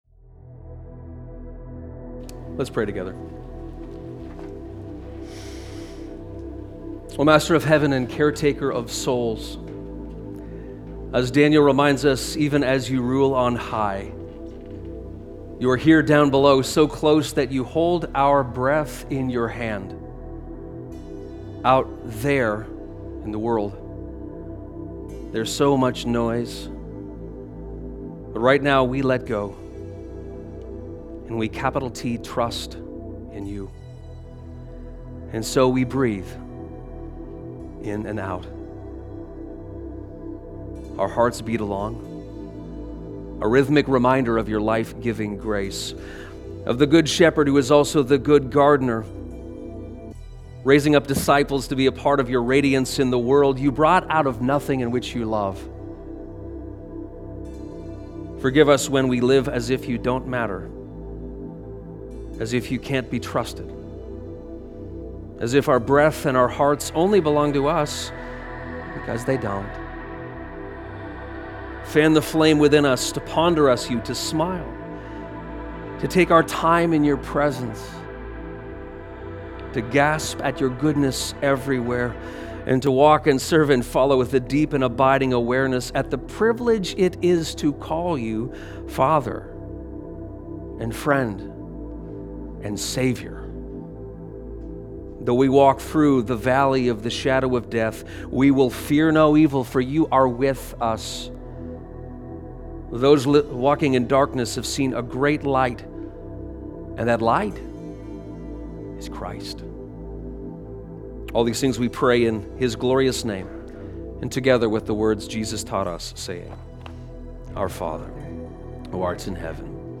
You hold our breath in your hand - a prayer